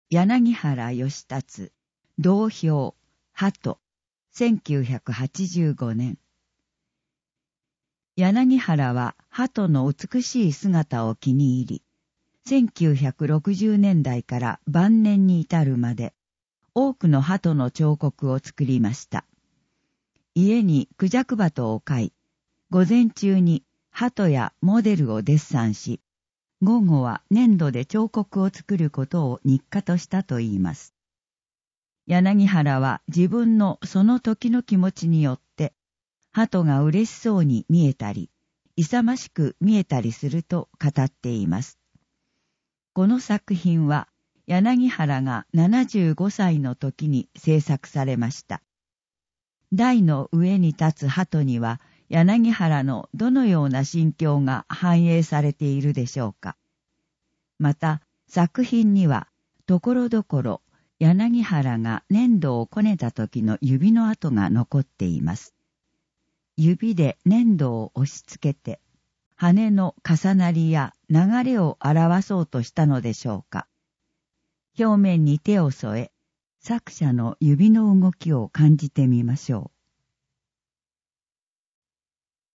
音訳